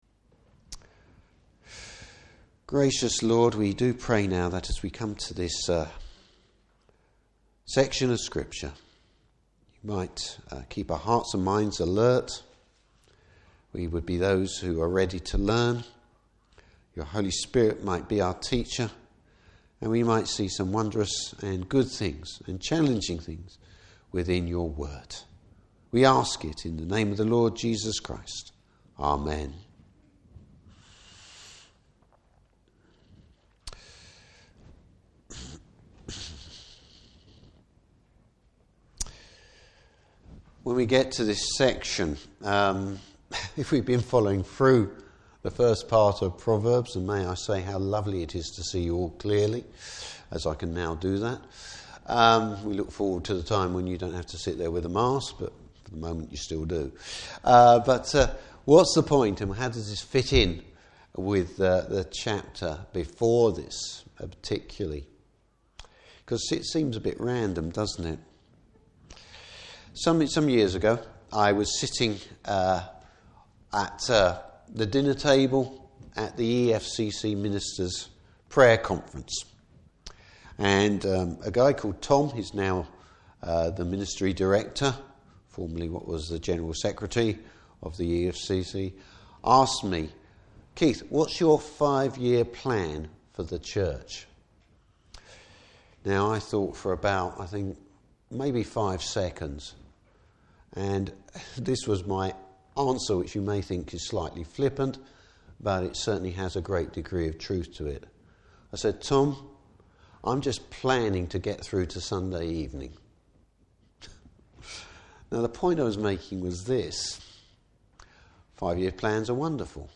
Service Type: Morning Service How wisdom should dictate our actions.